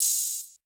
TS Open Hat 3.wav